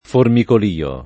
formikol&o] s. m. — es. con acc. scr.: vidi un formicolìo nero Di piccole ombre erranti per le dune [v&di un formikol&o n%ro di p&kkole 1mbre err#nti per le d2ne] (Pascoli); dover tenere i piedi penzoloni, cosa che dopo un po’ dà il formicolìo [dov%r ten%re i pL$di penzol1ni, k0Sa ke ddopo um p0 da il formikol&o] (Calvino) — cfr. formica